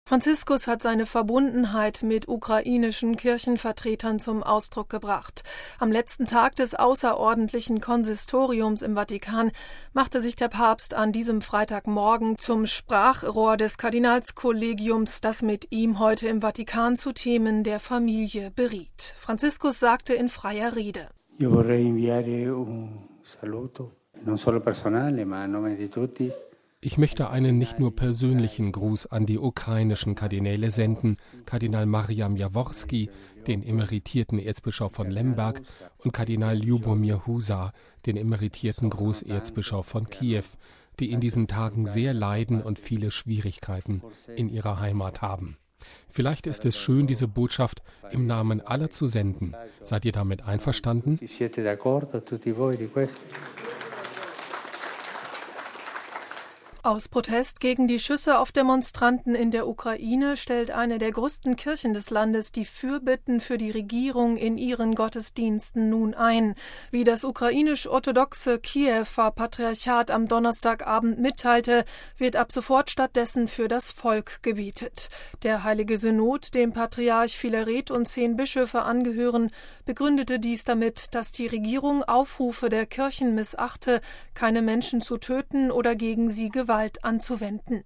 Franziskus sagte in freier Rede:
(Zustimmender Applaus)